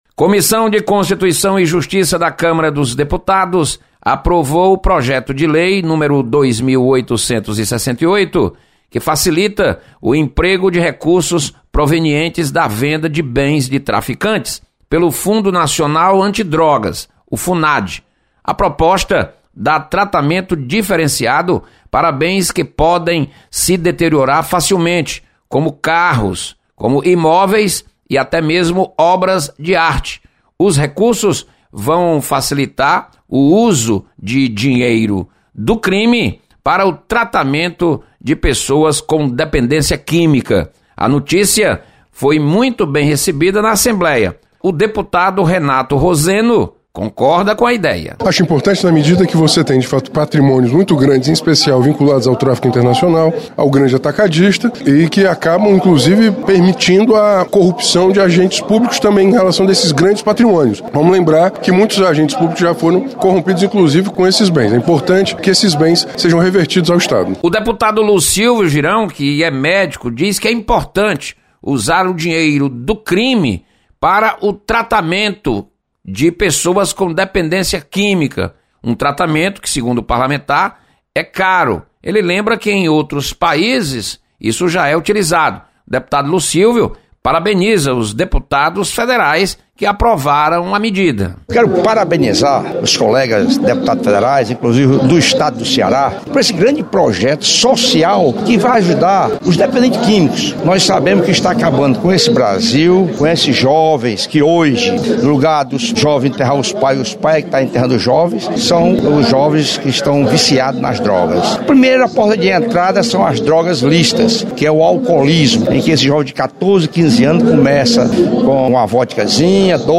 Deputados comentam sobre projeto que facilita uso de recursos provenientes da venda de bens de traficantes.